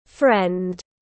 Bạn bè tiếng anh gọi là friend, phiên âm tiếng anh đọc là /frend/.